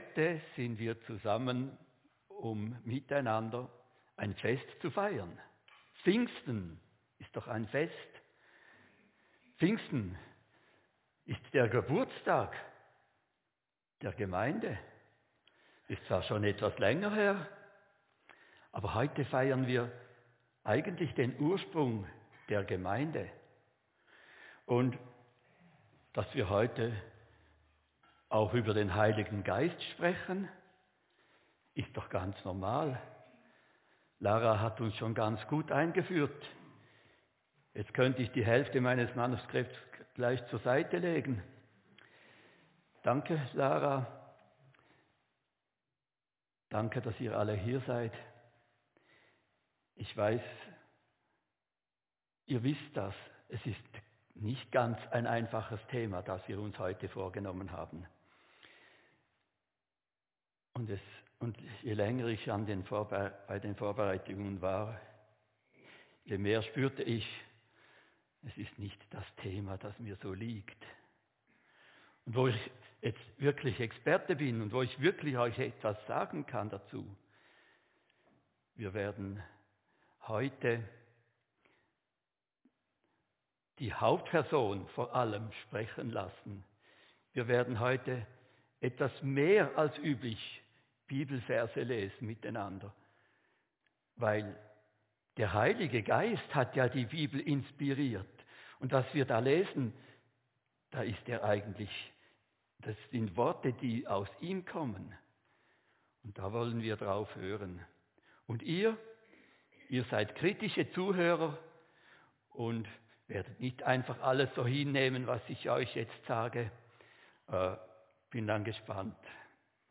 Predigt-19.5.24.mp3